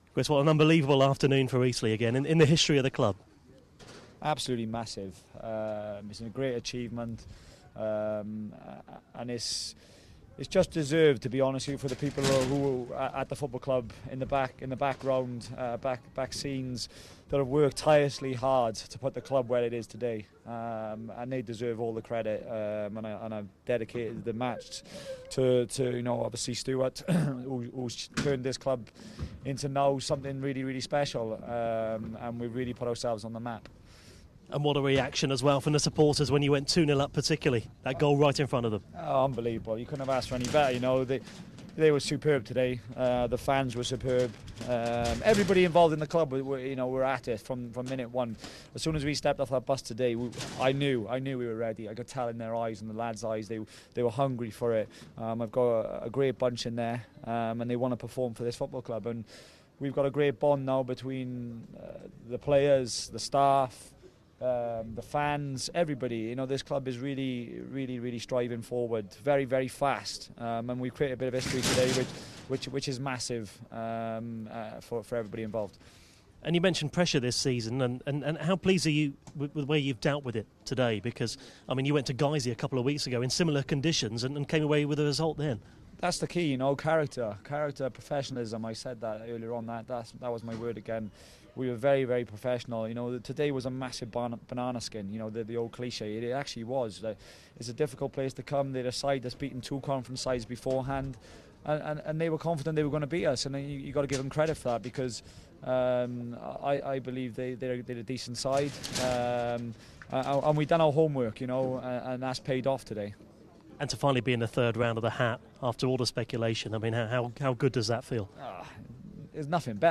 REACTION